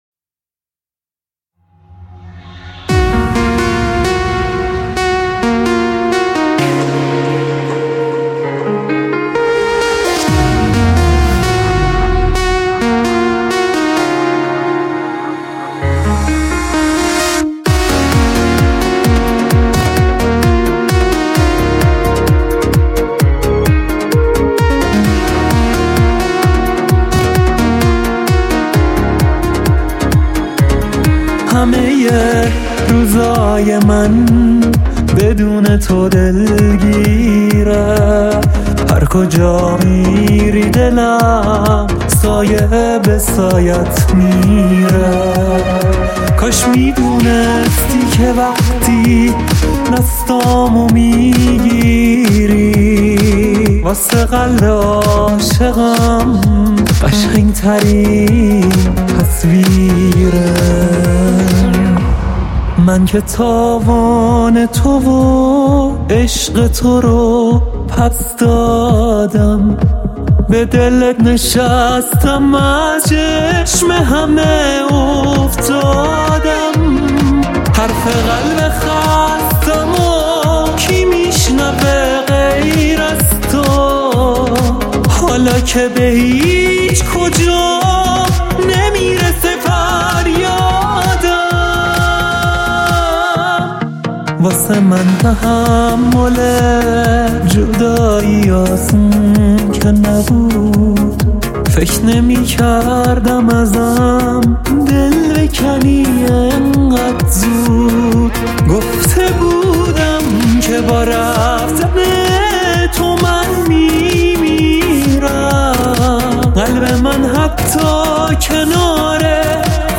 آهنگهای پاپ فارسی